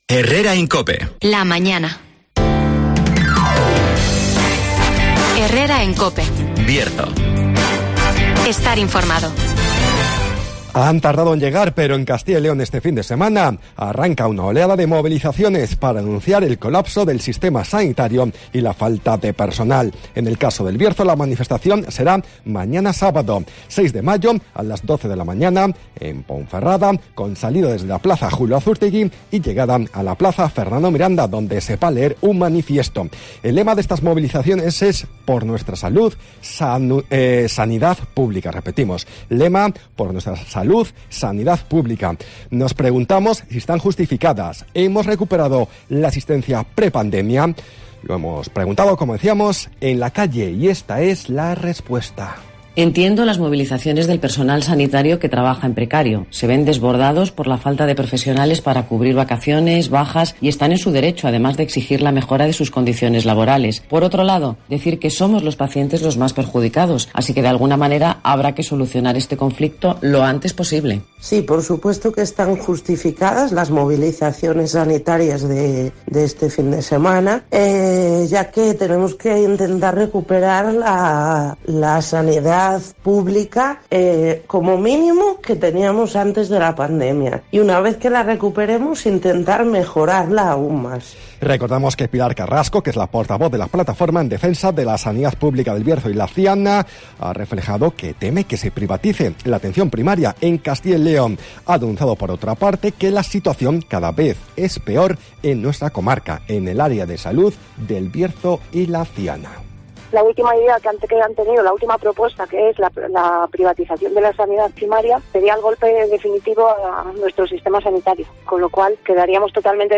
-Resumen de las noticias -El tiempo -Agenda -Cabañas Raras conmemora este viernes 5 de mayo su emancipación del Señorío de Arganza (Entrevista